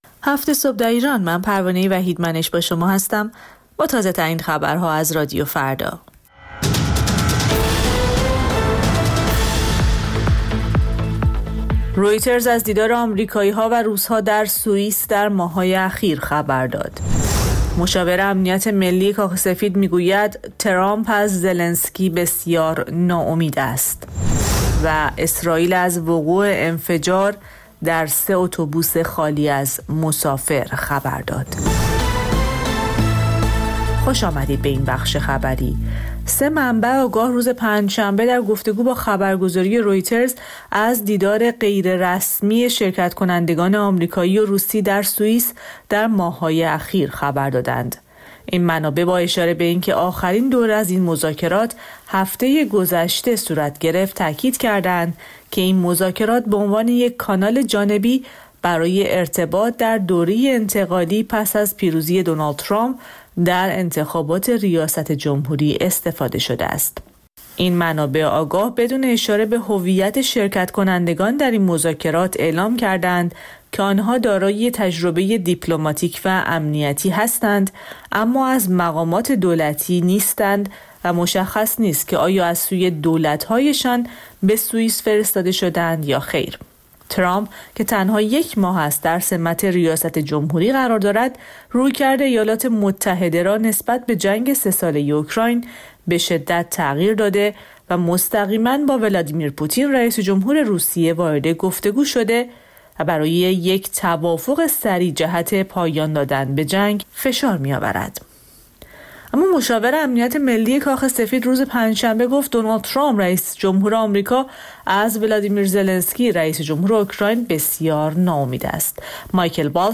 سرخط خبرها ۷:۰۰